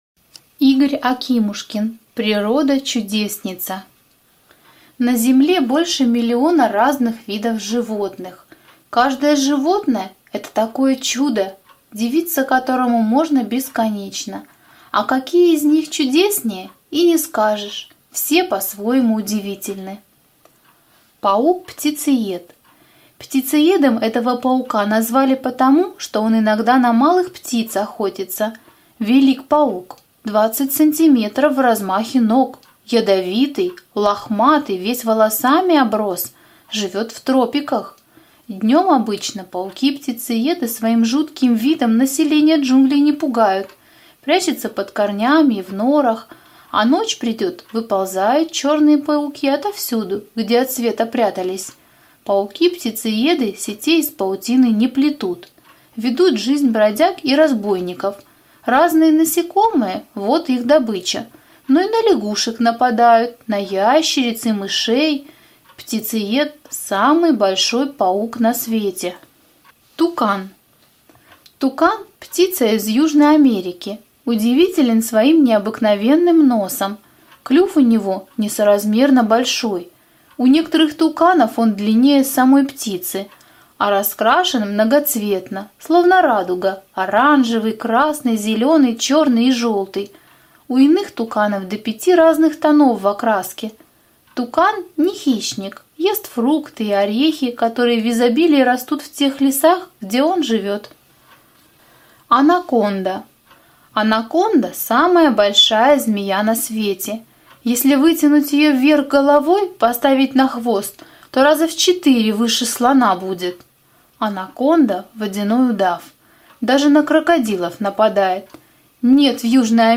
На данной странице вы можете слушать онлайн бесплатно и скачать аудиокнигу "Природа-чудесница" писателя Игорь Акимушкин.